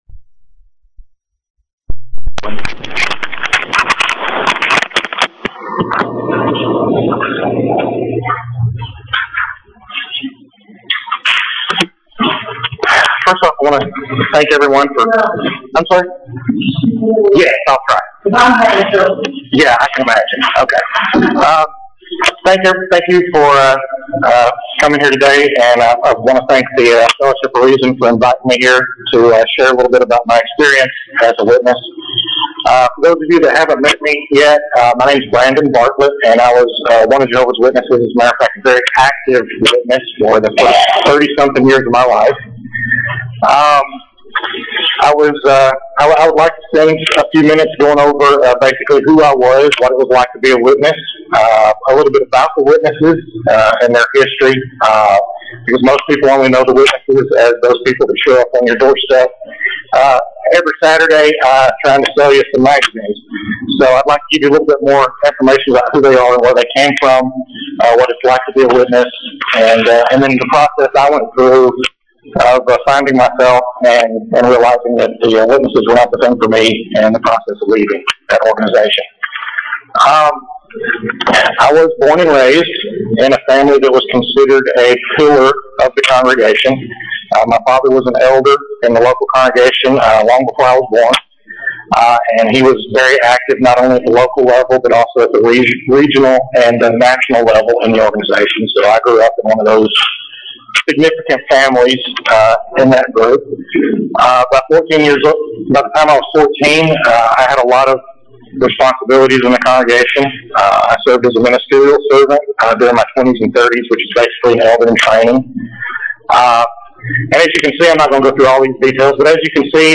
Oratory